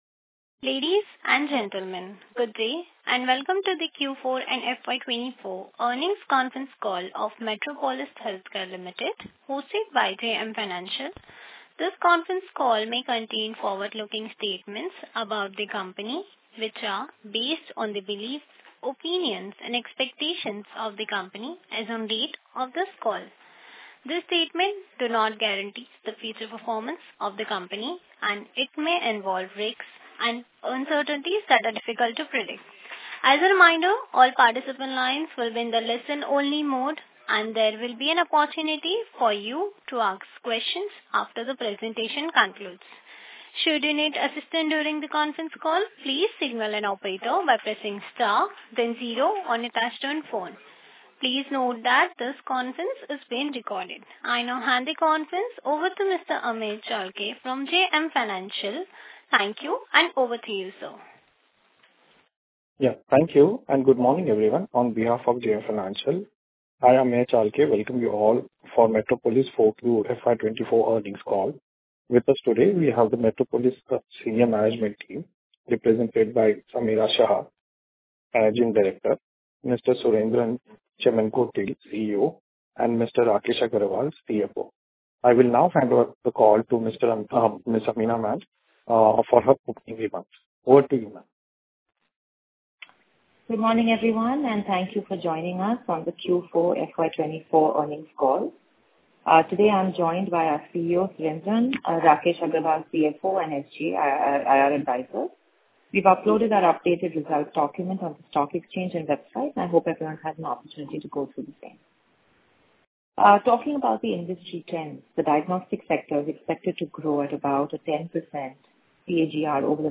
Concalls
Metropolis-Q4FY24-Earnings-Call-Audio.mp3